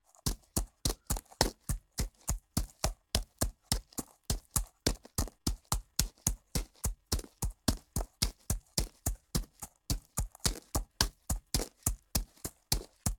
horse_walk_dirt.ogg